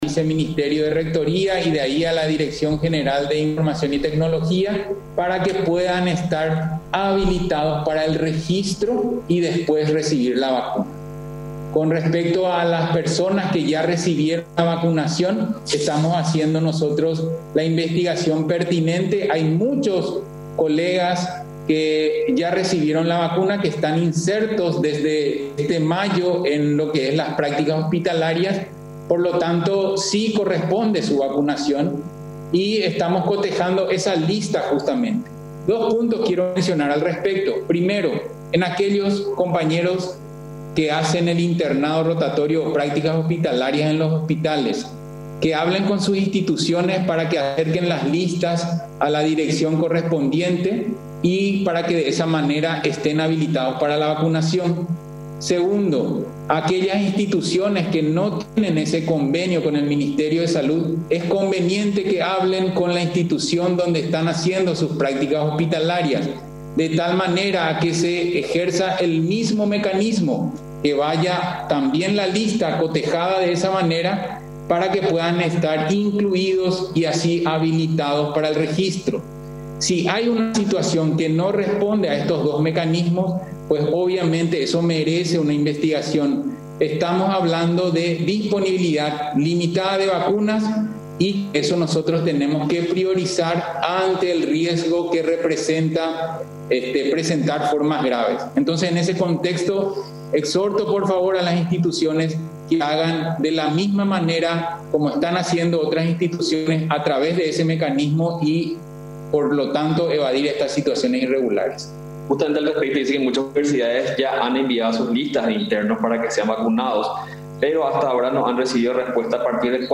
El anuncio fue hecho en conferencia por el Dr. Héctor Castro, director del Programa Ampliado de Inmunizaciones (PAI), y la Dra. Leticia Pintos, directora de Redes y Servicios de Salud del Ministerio de Salud, quienes admitieron que se producirá falta de dosis anti-COVID en algunos vacunatorios del país, razón por la que no se completará el plan de vacunación de esta semana, como se preveía inicialmente para las personas que deben recibir la primera dosis.